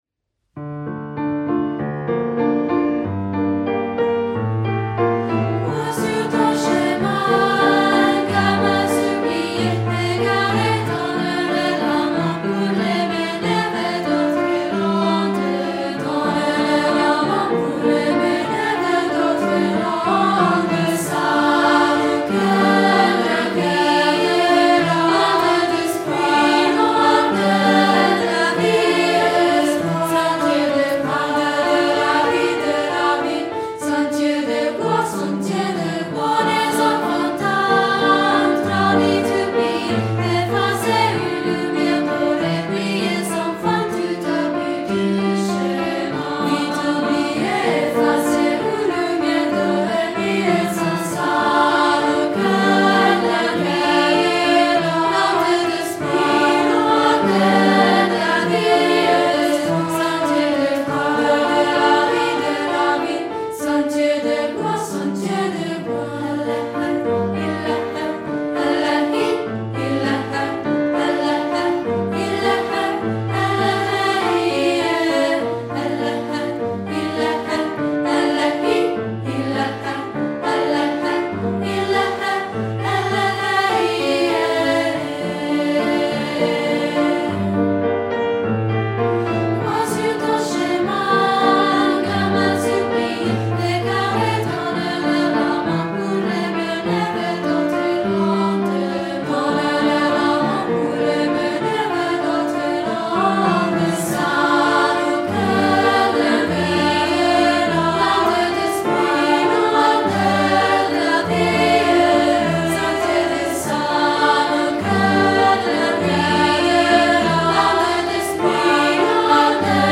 ‹ Coro di Voci Bianche